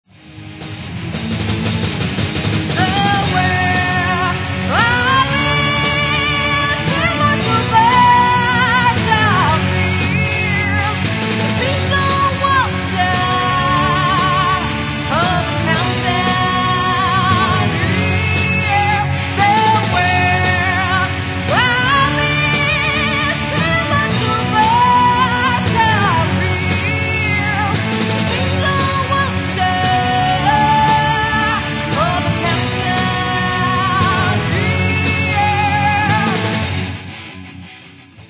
about Metal Bands in Italy